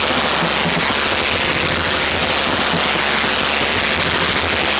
tesla-turret-chain-beam.ogg